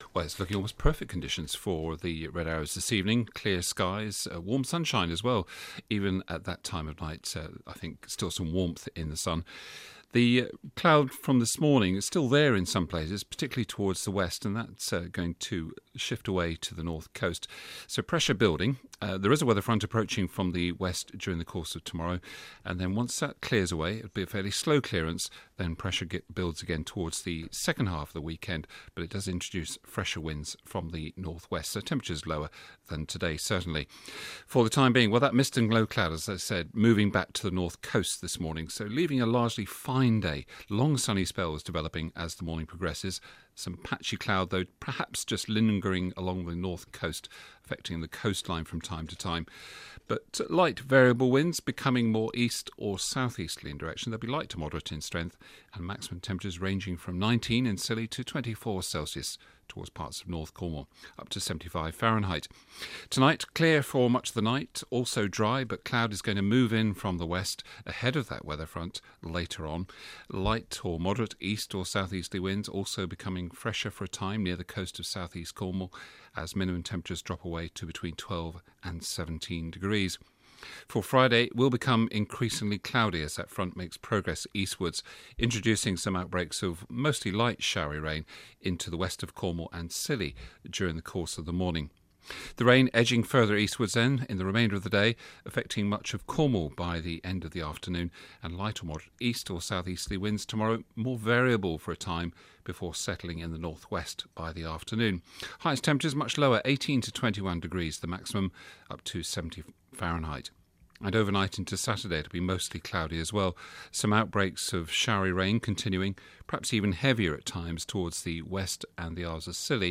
5 day forecast for Cornwall and Scilly from 8.15AM on 22 August